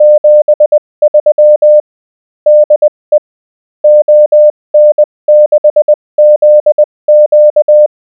The GNU Octave program below generates a sound file (.wav) with the morse code representing a given text.
Smoother...
MorseSoundFileGenerator_1.wav